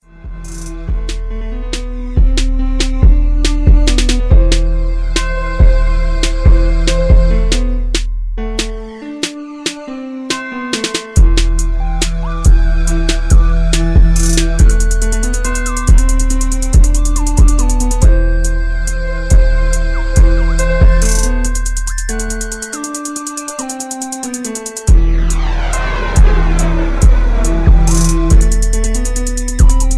rap beat